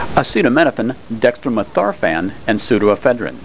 Pronunciation
(a seet a MIN oh fen, deks troe meth OR fan, & soo doe e FED rin)